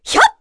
Erze-Vox_Attack2_kr.wav